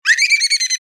Dolphin-calling-2.mp3